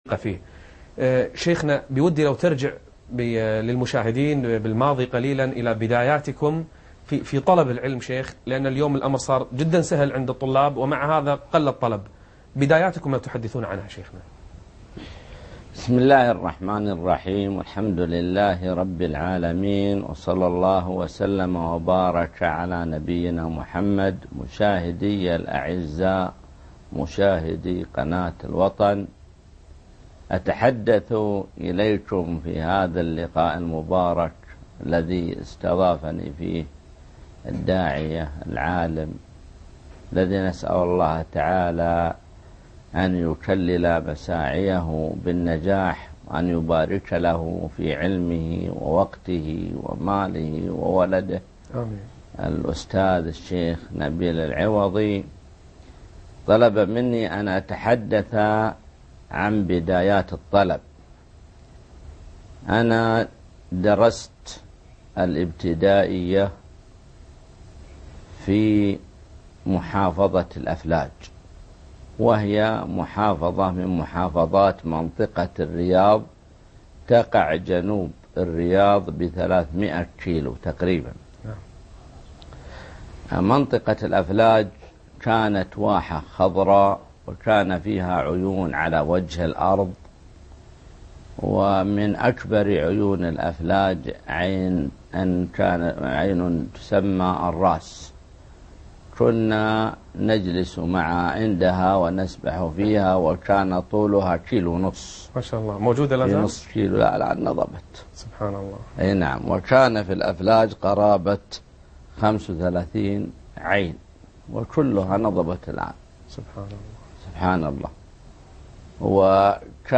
لقاء مع الشيخ عبد الله المطلق - فضيلة الشيخ نبيل العوضي